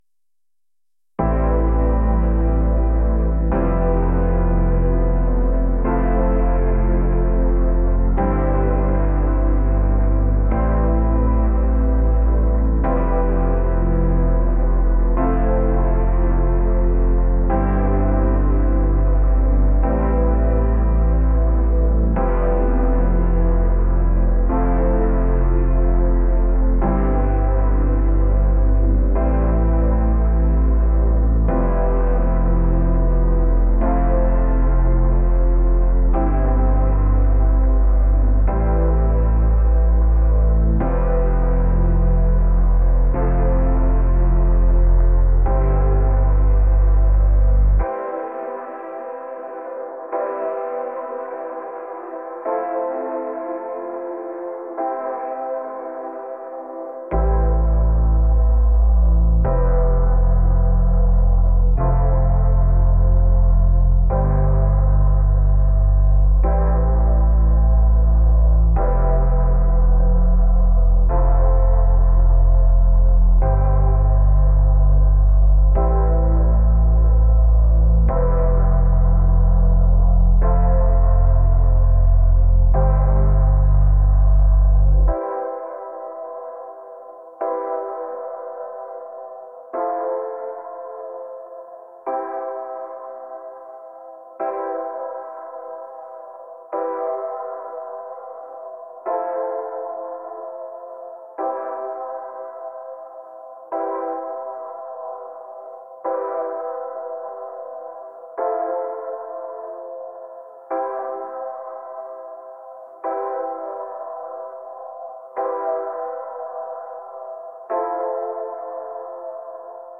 electronic | dreamy